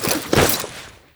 goprone_02.wav